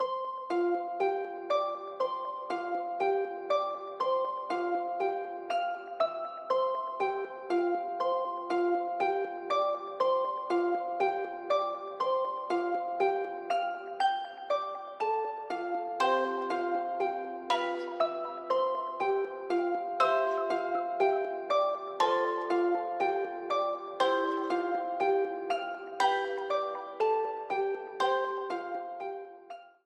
A yellow streamer theme
Ripped from the game
clipped to 30 seconds and applied fade-out